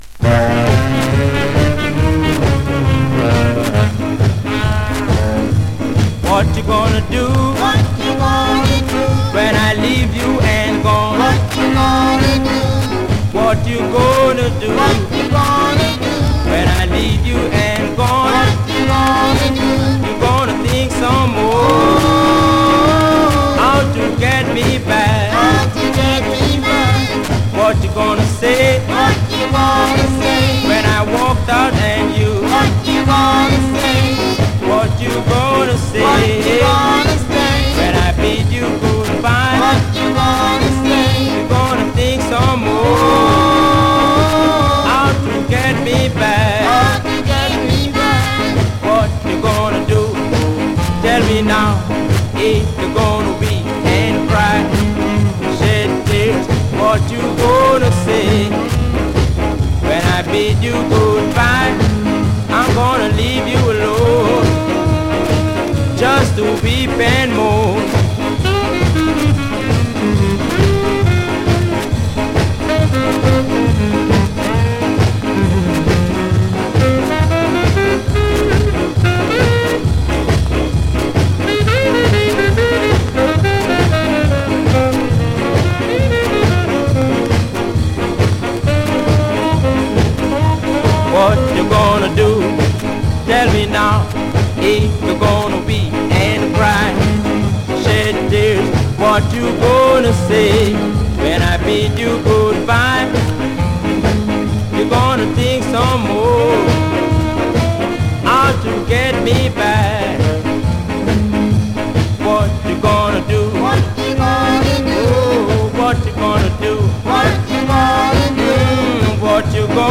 early hard driving, pounding Jamaican R&B
a sweet horn break